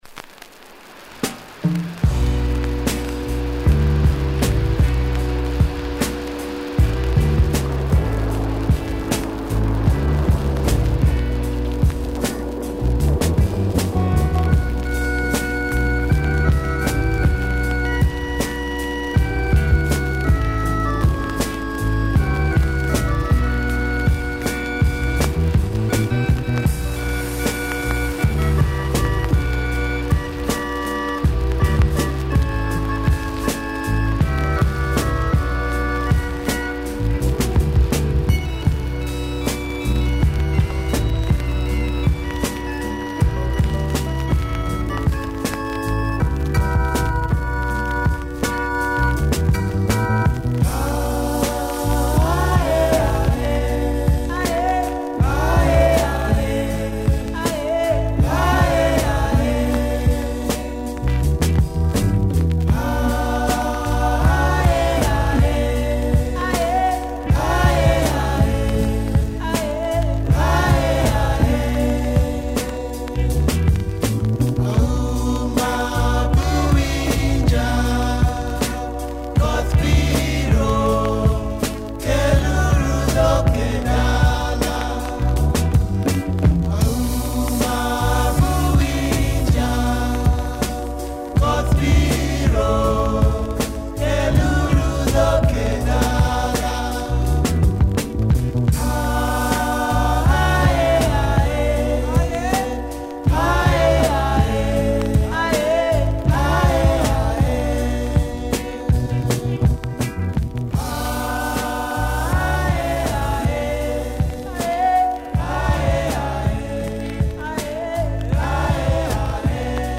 Slighly psych ridden afro-rock two sider